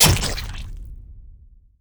KnifeHit.wav